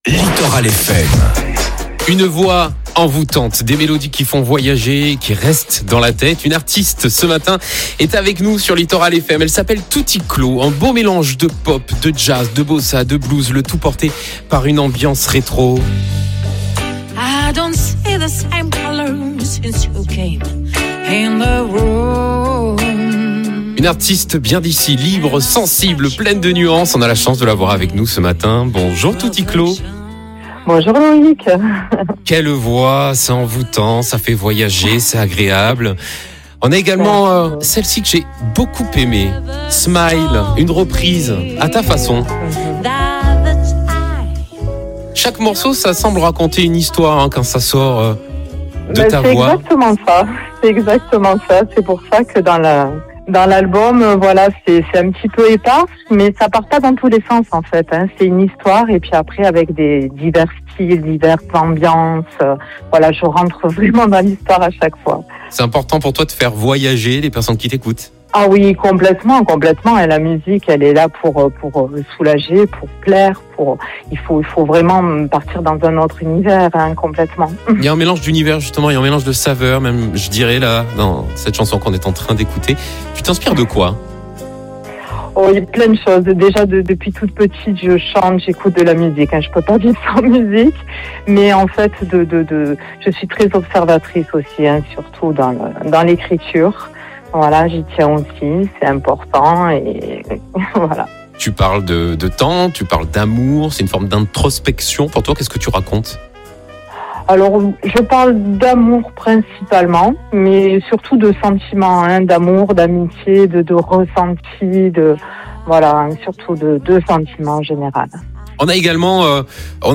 une voix singulière, entre douceur et intensité